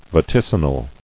[va·tic·i·nal]